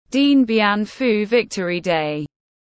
Ngày chiến thắng Điện Biên Phủ tiếng anh gọi là Dien Bien Phu Victory Day, phiên âm tiếng anh đọc là /ˌdjɛn bjɛn ˈfuː ˈvɪktəri deɪ/
Dien Bien Phu Victory Day /ˌdjɛn bjɛn ˈfuː ˈvɪktəri deɪ/